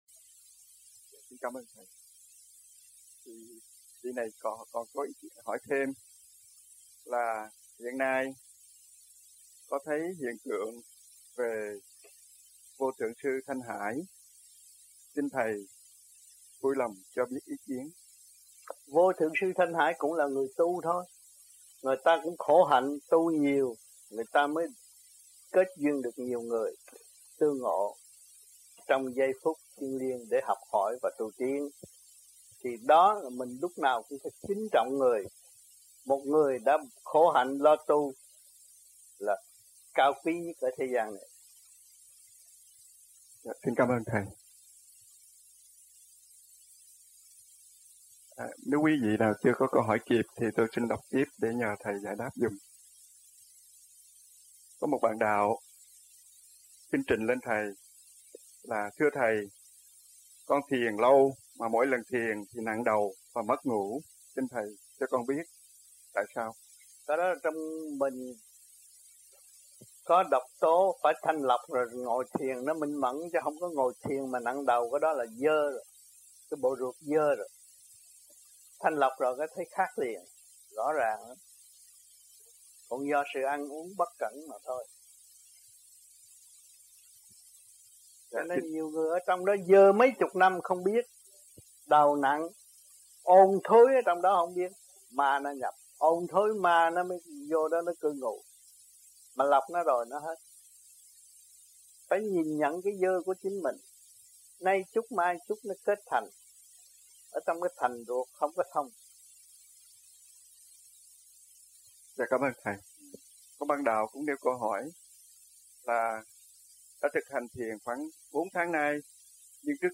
Australia Trong dịp : Khóa sống chung >> wide display >> Downloads